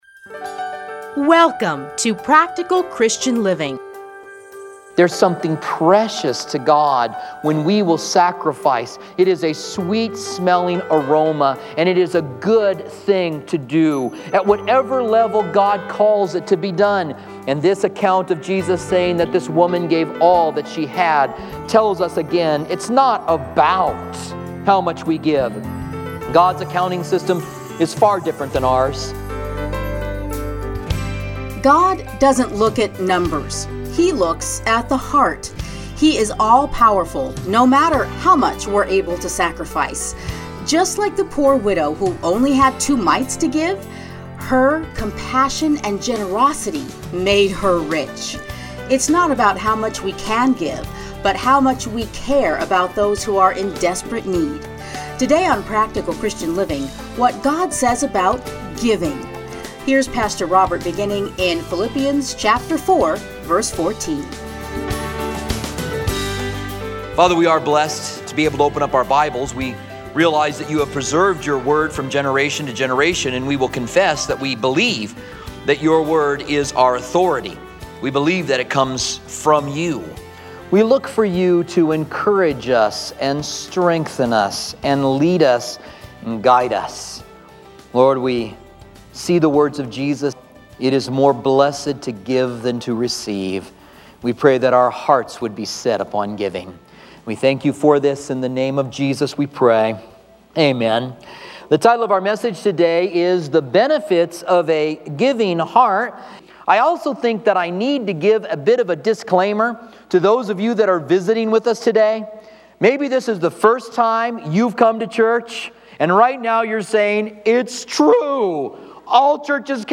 edited into 30-minute radio programs